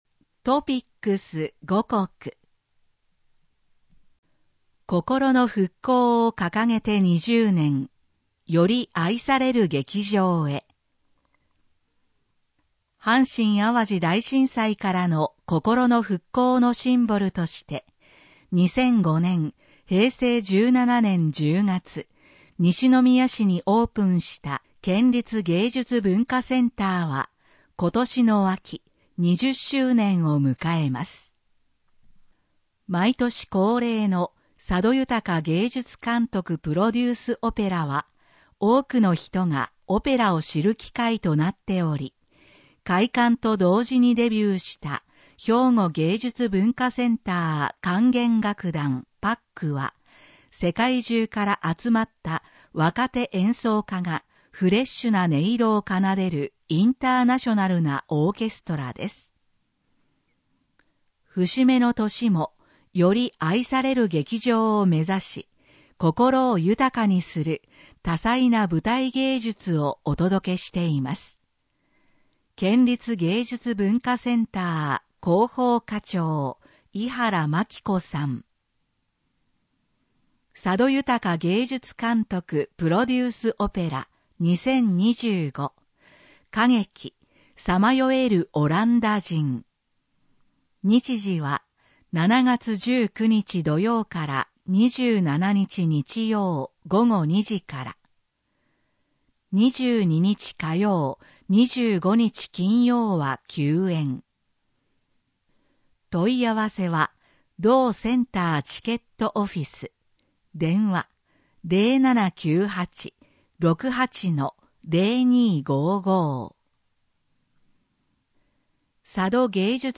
県民だより2025年6月号音声版